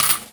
R - Foley 146.wav